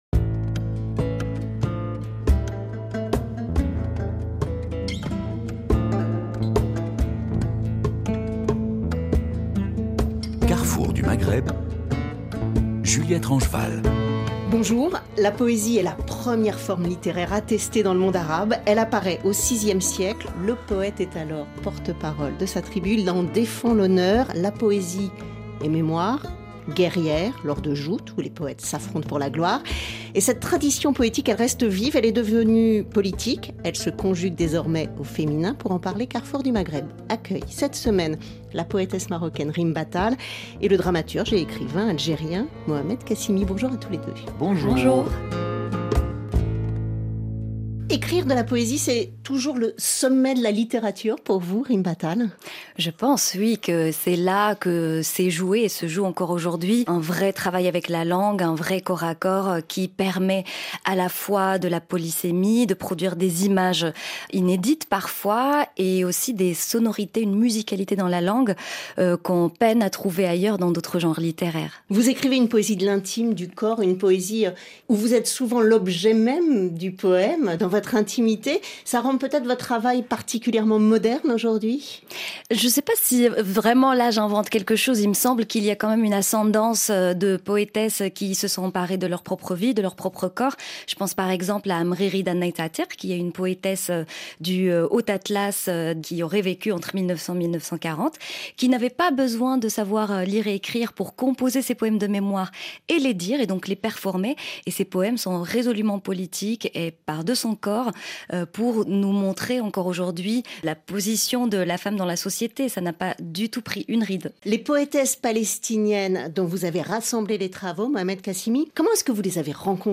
Pour explorer les enjeux des sociétés algérienne, libyenne, marocaine, mauritanienne et tunisienne ; leurs liens, notamment, avec le continent africain. Reportages des correspondants dans la région et rencontres, entre Méditerranée au Nord, et Sahara au Sud, avec ceux qui pensent et ceux qui font le Maghreb aujourd'hui.